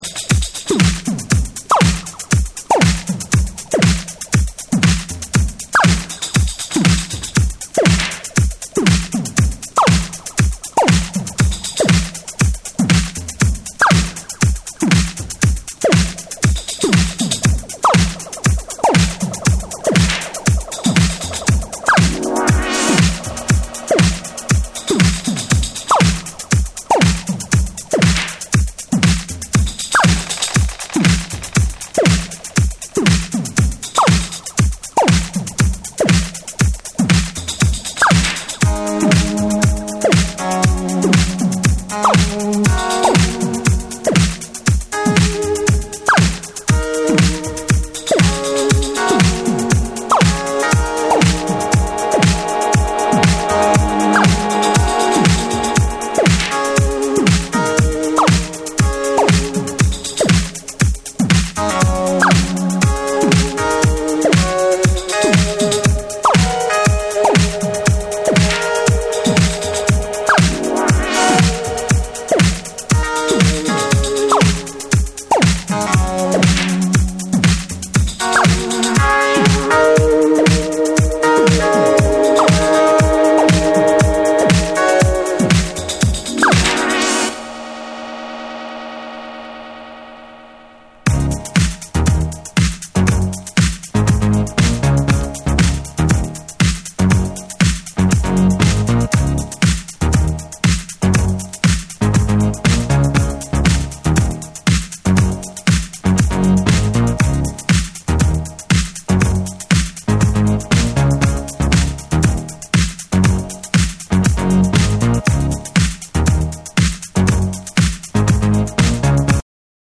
歪んだファンク感覚と脱力したグルーヴが同居する
よりストレートに腰を揺らすオリジナルを収録。
TECHNO & HOUSE / DISCO DUB